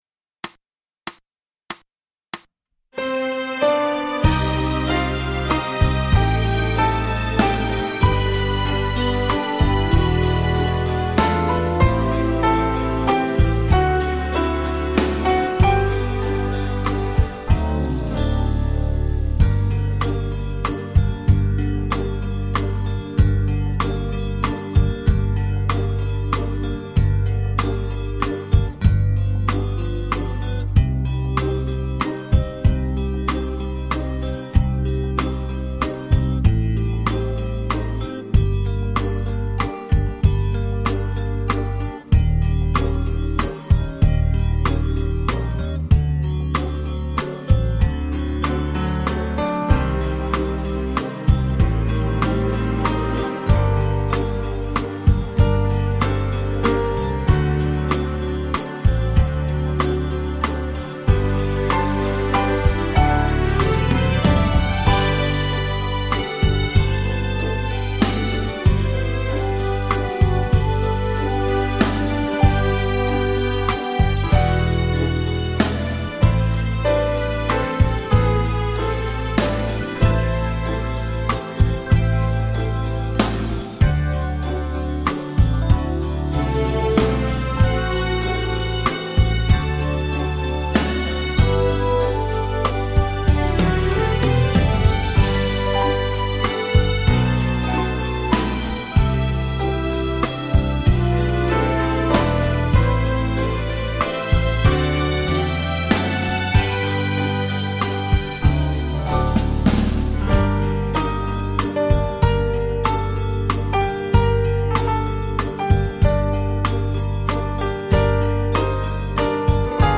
Genere: Valzer Lento
Scarica la Base Mp3 (3,58 MB)